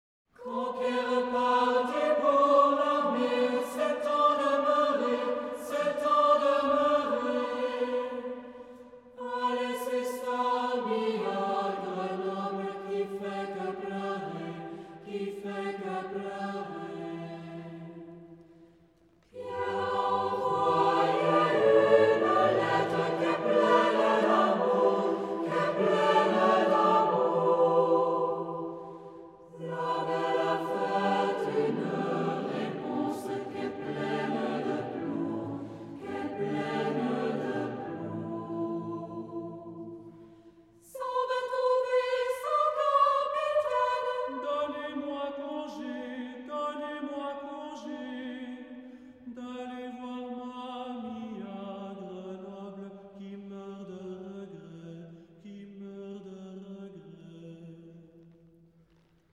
French specialists in choral singing!
SATB a cappella
for SATB a cappella choir a cappella.